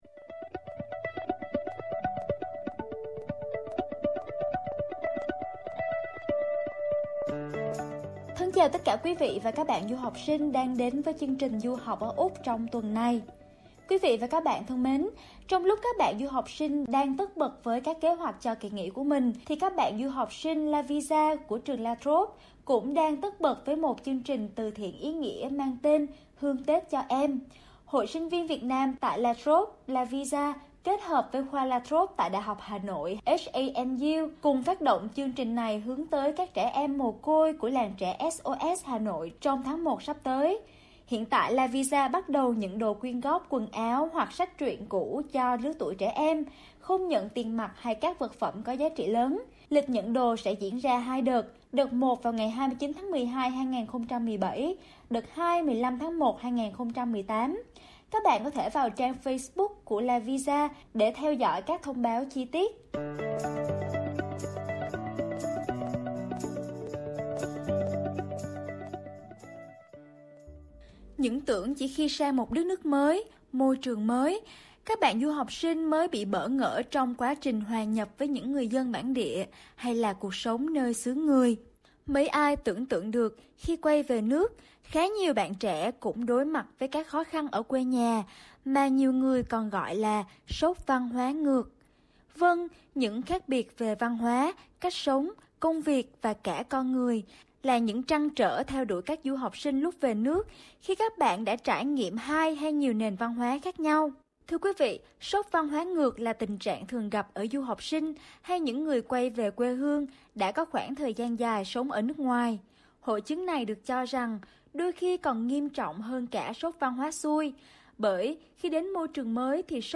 Cùng lắng nghe cuộc trò chuyện với 2 cựu du học sinh đã quay về Việt Nam.